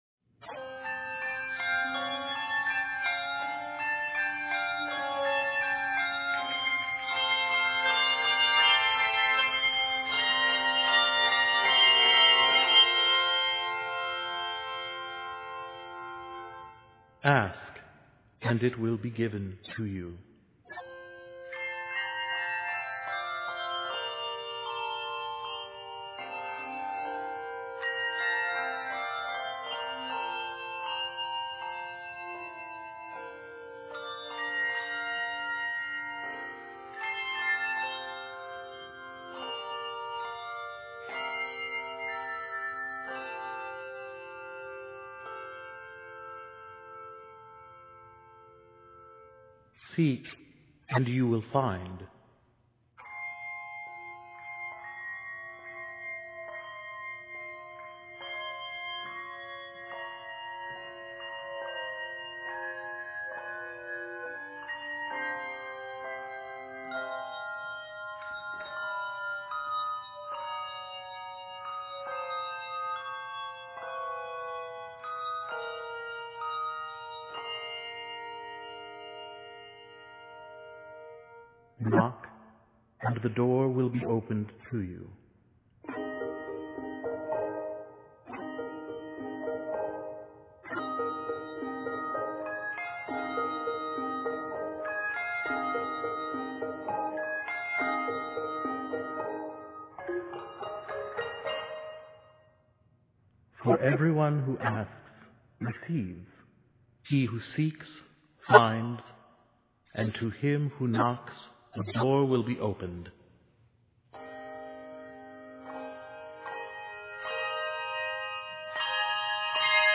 handbells
N/A Octaves: 3 Level